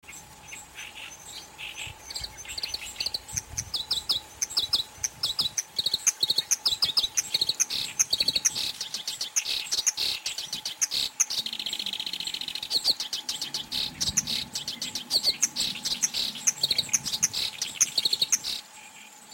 ヨ シ キ リ 属   コヨシキリ １　　1-04-13
鳴 き 声：地鳴きは草の中でジッジッと鳴く。オオヨシキリより細い声でジョッピリリ、ジョッピリリ、ギョッギョキリキリ、チリリ等と早口で
鳴き声１